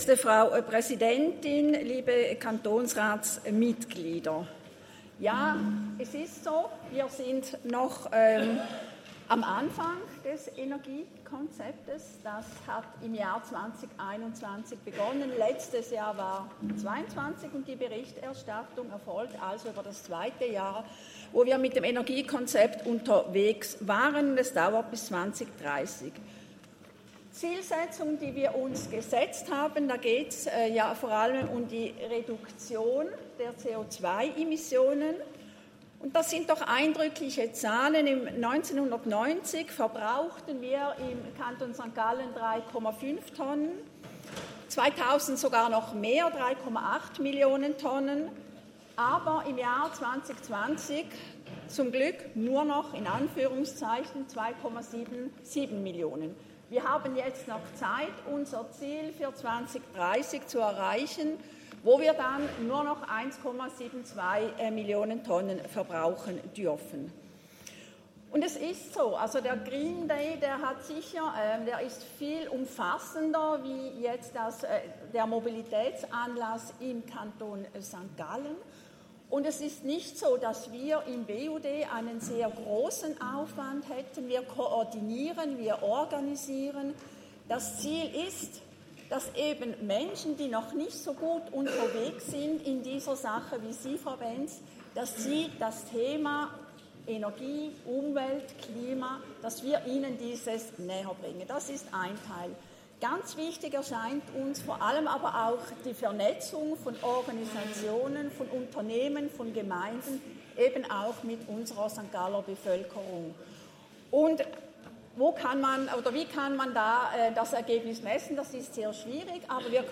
Session des Kantonsrates vom 12. bis 14. Juni 2023, Sommersession
12.6.2023Wortmeldung